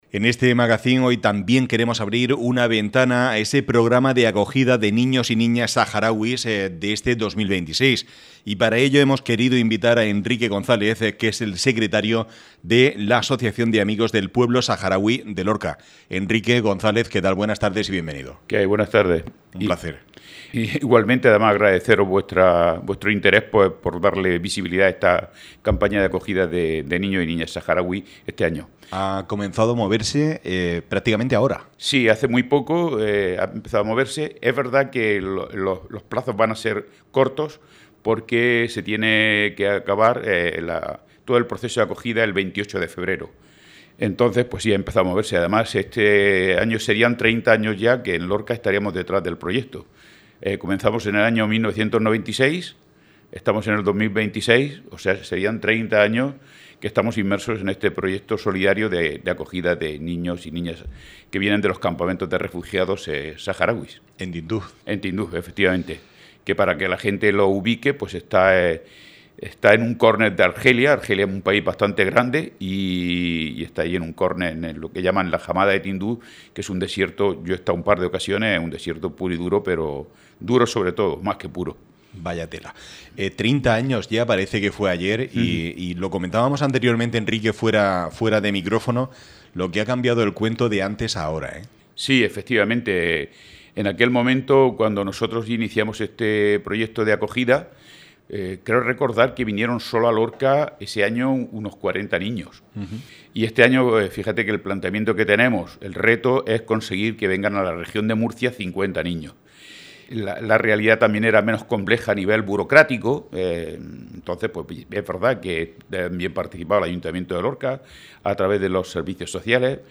ÁREA LORCA RADIO.